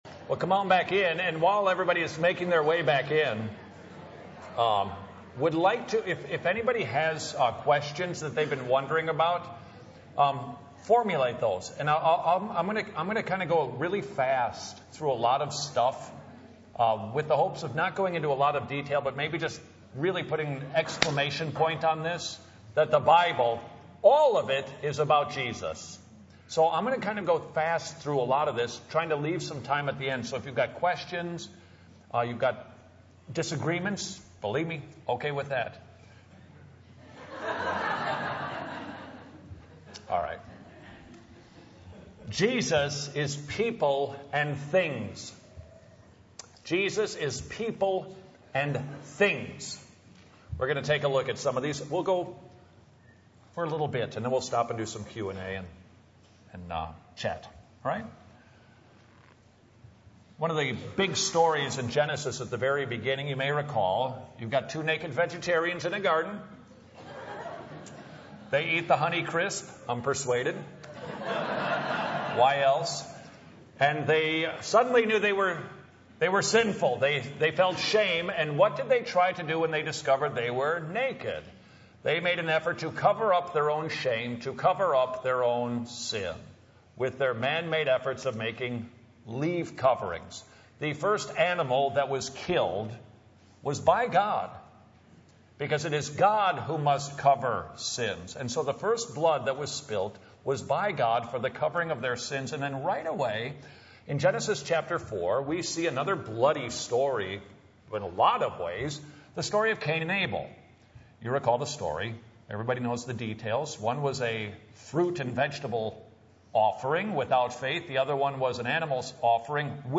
* Note: Audio quality problems are not the fault of your audio device